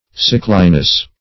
Sickliness \Sick"li*ness\
sickliness.mp3